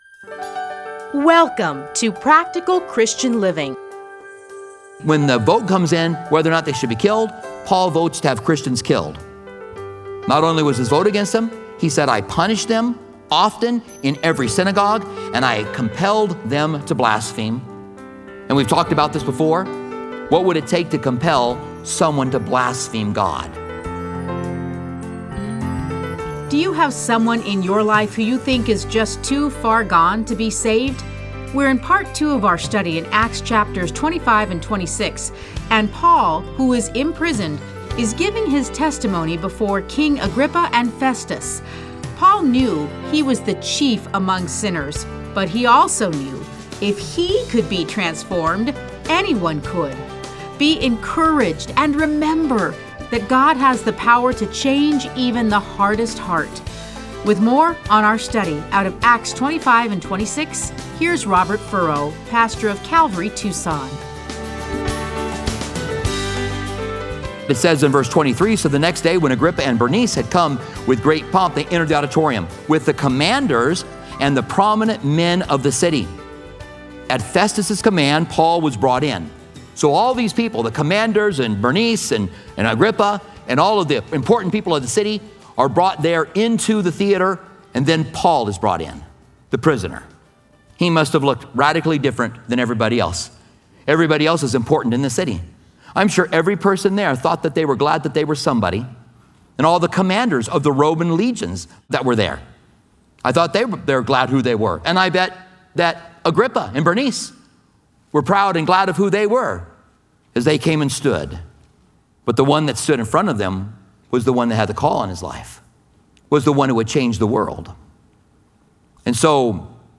Listen to a teaching from Acts 25-26.